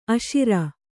♪ aśira